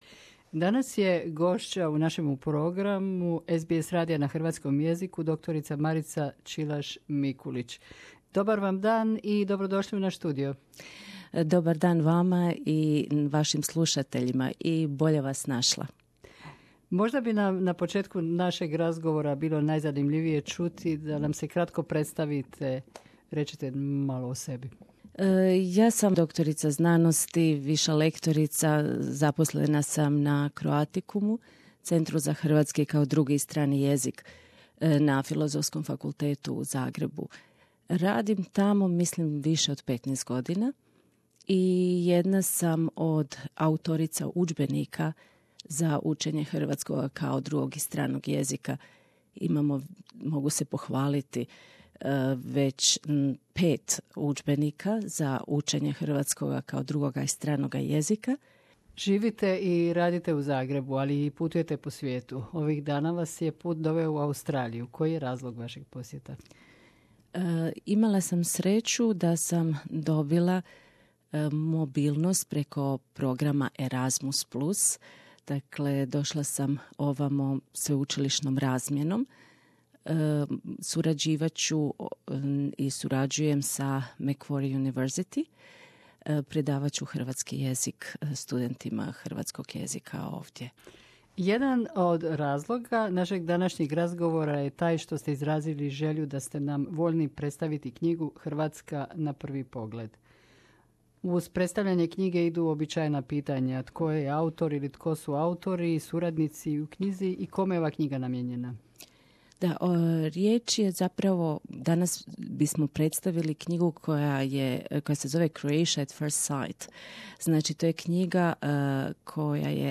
at SBS studio